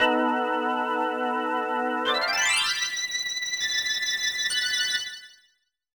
Minigame intro music